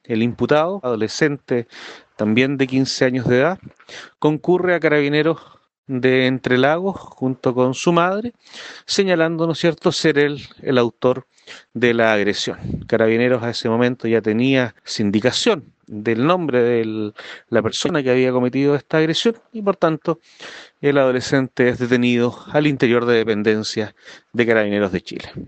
El fiscal Narciso García, de la Fiscalía Local de Osorno, informó los hechos que mantienen detenido a un joven de 15 años de edad, quien compartía colegio con el menor fallecido.